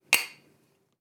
Abrir el tapón de una botella de cristal
chapa
Sonidos: Acciones humanas
Sonidos: Hogar